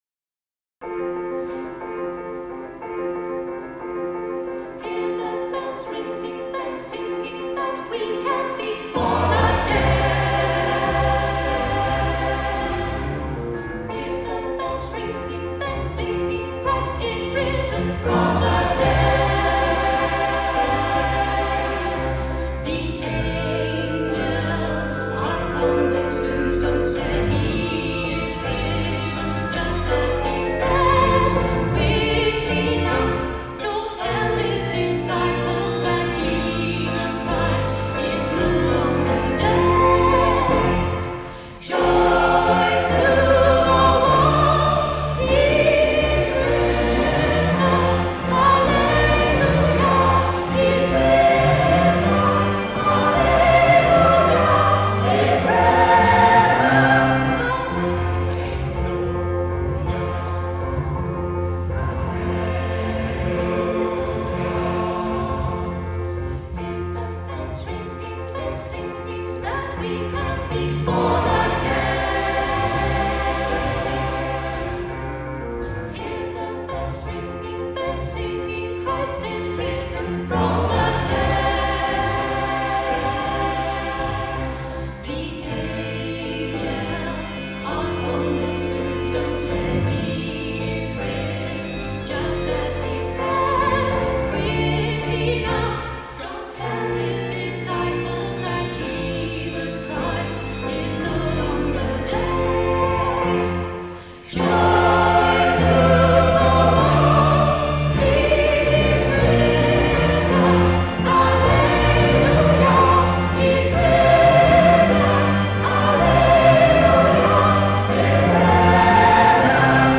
Soloist & Choir
This recording was made in early 1979.